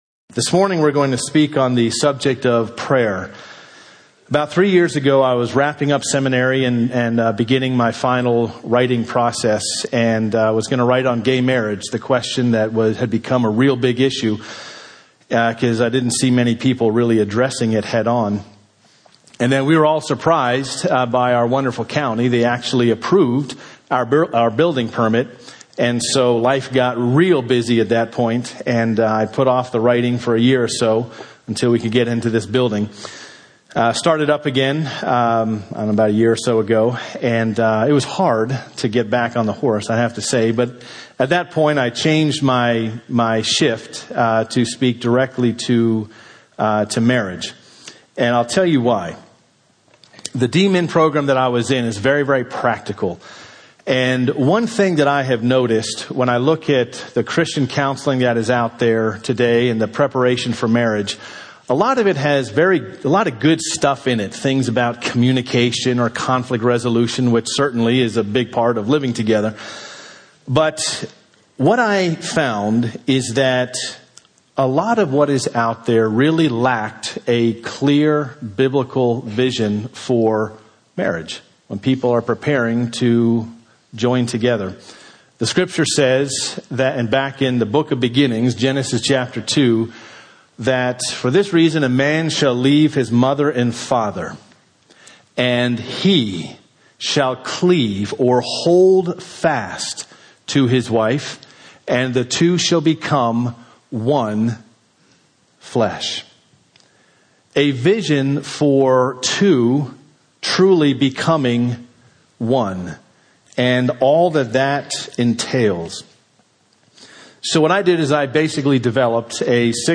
Ecclesiastes Series message 7 Text: I Thessalonians 5:16-18 On March 22nd